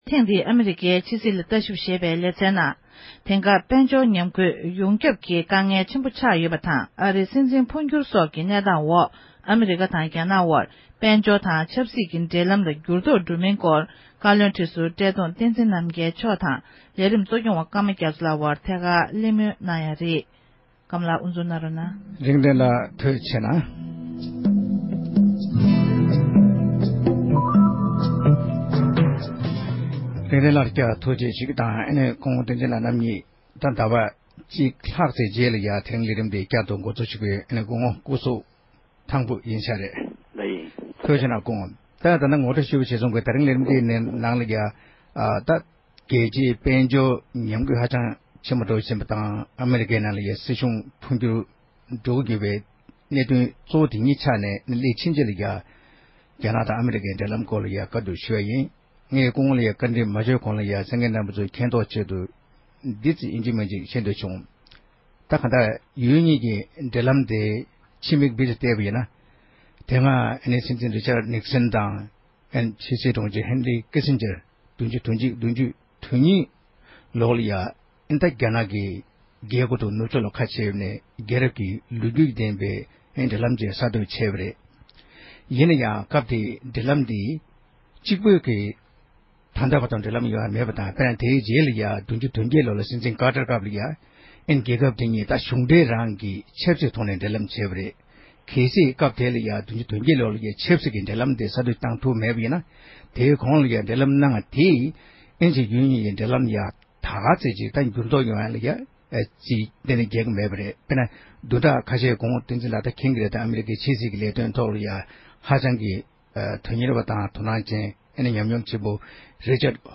བགྲོ་གླེང་གནང་བ་ཞིག་གསན་རོགས་གནང༌༎